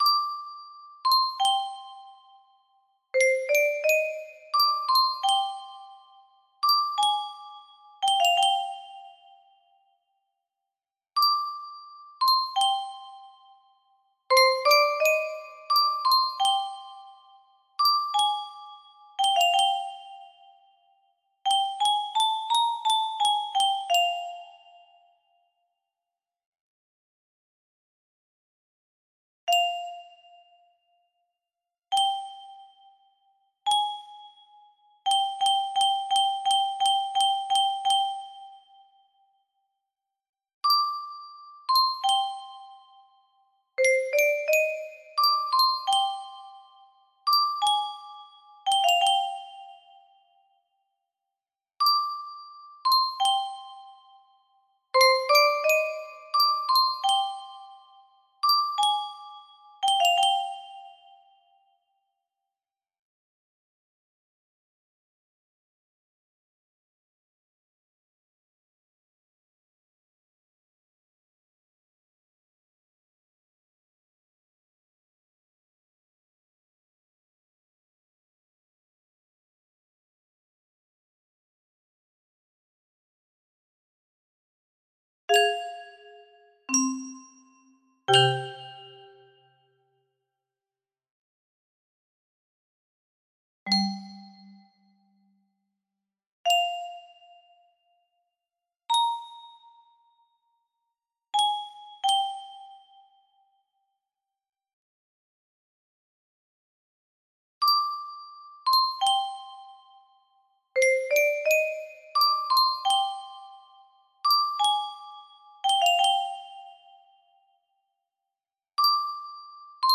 Movie Theme adapted for 30 notes